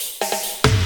Fill.wav